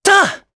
Evan-Vox_Attack4_jp.wav